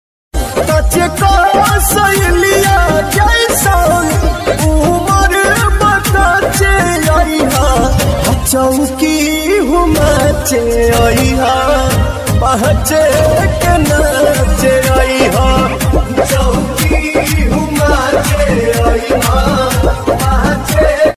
bhojpuri ringtone mp3